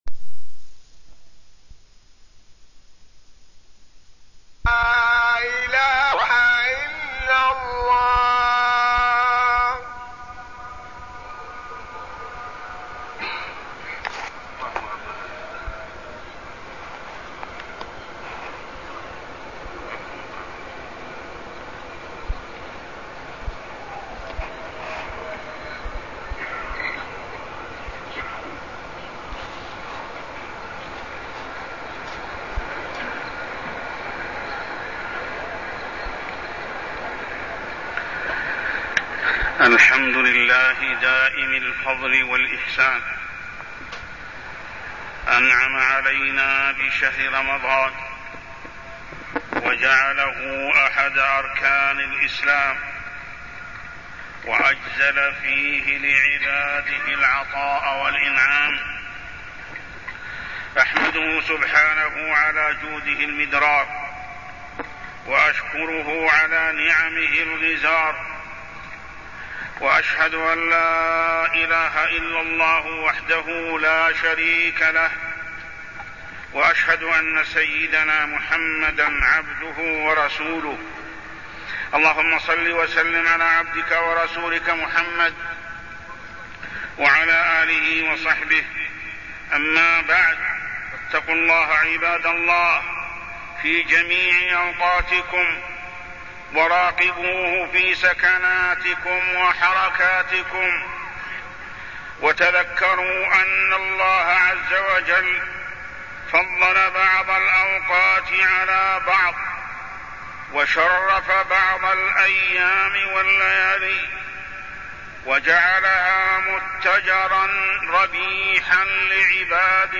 تاريخ النشر ٢ رمضان ١٤٢٠ هـ المكان: المسجد الحرام الشيخ: محمد بن عبد الله السبيل محمد بن عبد الله السبيل فريضة صوم رمضان